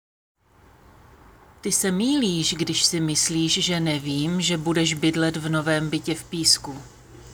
Tady si můžete stáhnout audio na výslovnost I a Y: Ty se mýlíš – rychle.
Ty-se-mýlíš_rychle_lektorka.m4a